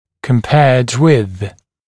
[kəm’peəd wɪð][кэм’пэад уиз]по сравнению с